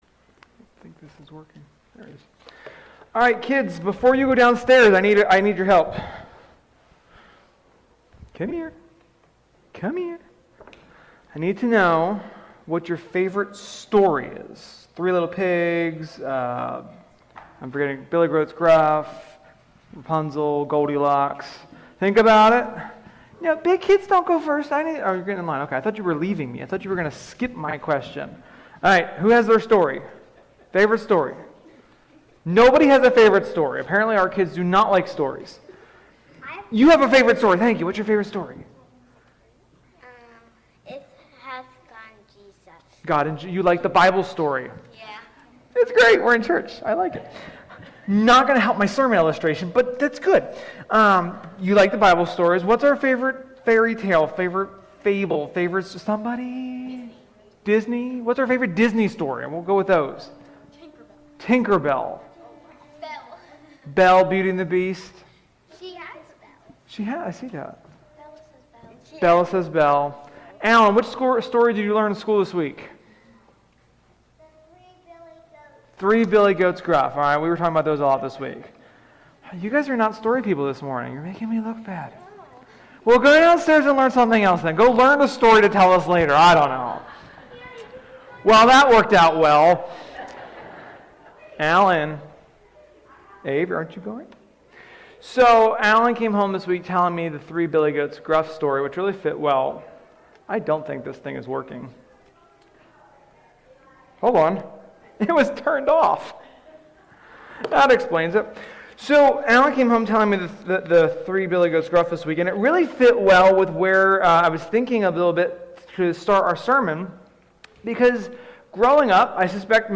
Sermon-9.12.21.mp3